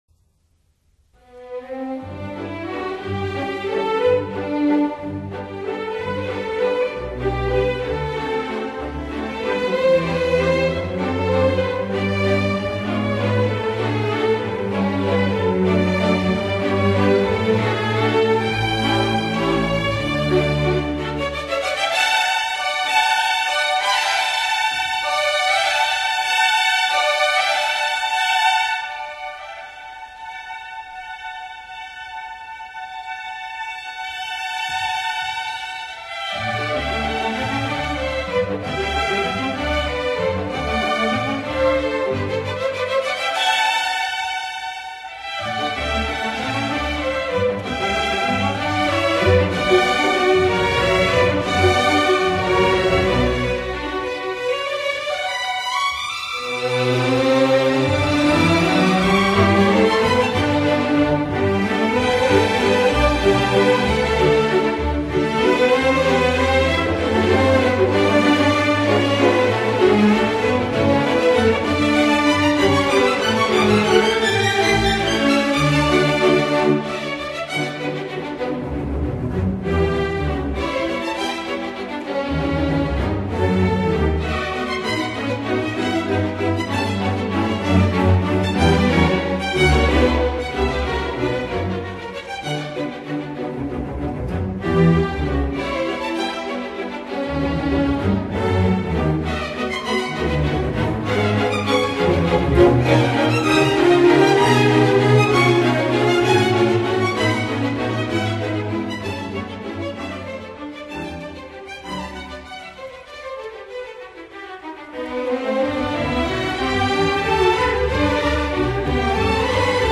Ensemble de cordes